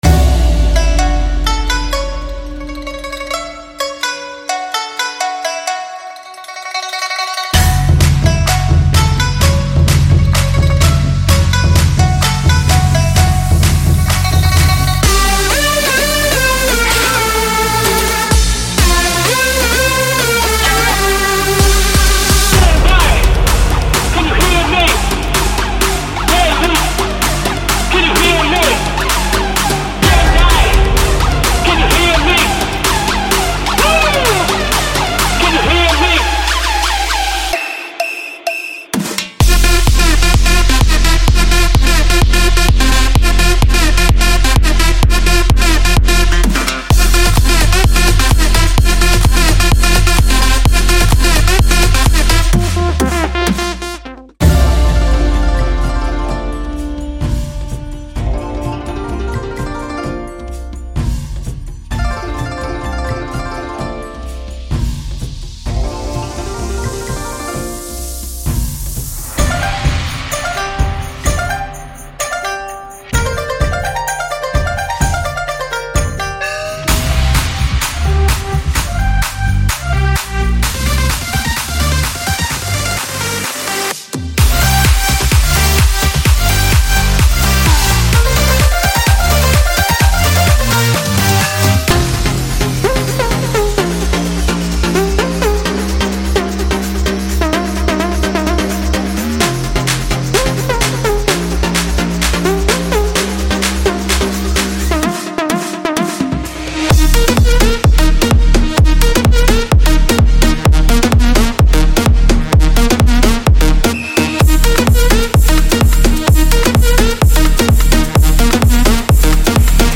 所有带有明确音调的声音均标有精确的Key
Core乐器音色映射和编程